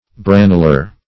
Branular \Bran"u*lar\, a. Relating to the brain; cerebral.